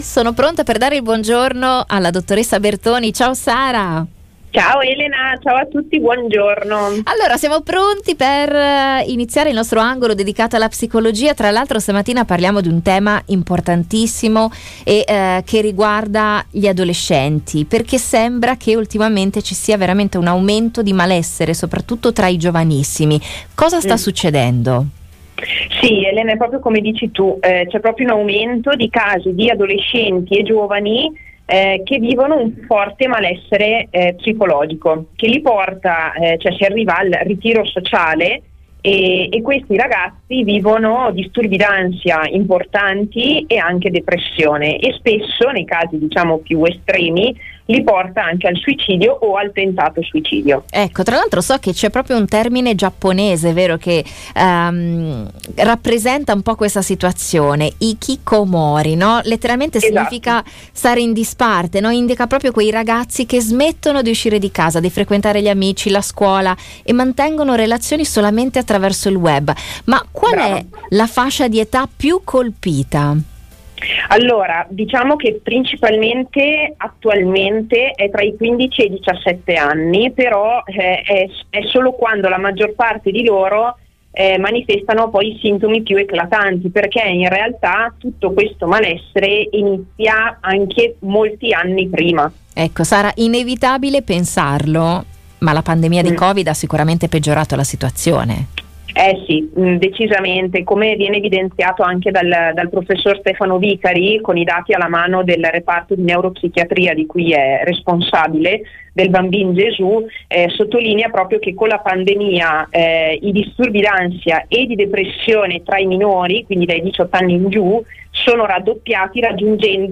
Qui l’intervento completo in diretta: